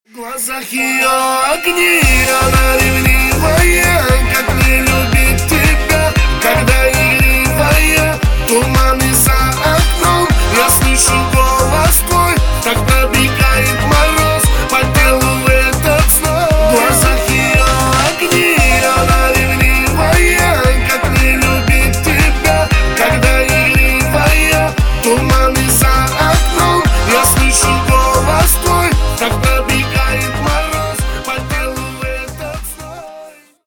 Поп Музыка # кавказские